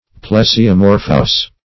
\Ple`si*o*mor"phous\